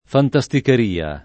fantasticheria [ fanta S tiker & a ] s. f.